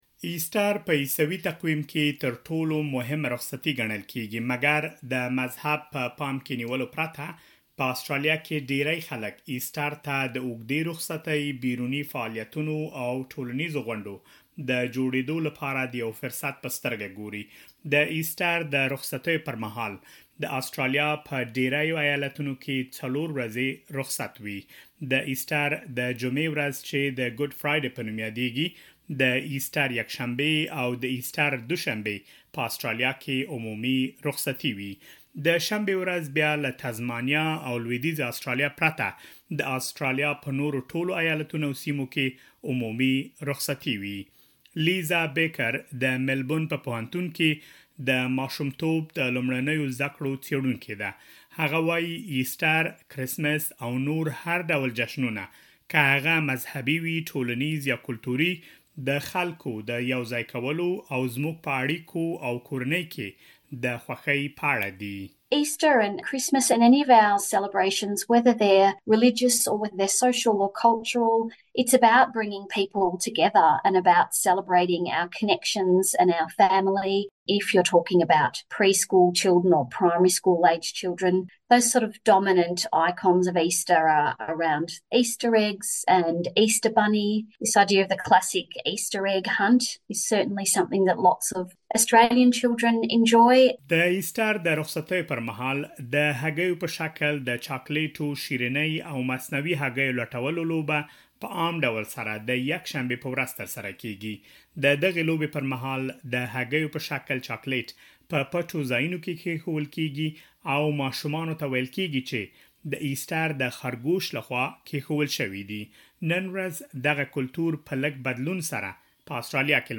مهرباني وکړئ لا‌ دېر معلومات په رپوټ کې واورئ.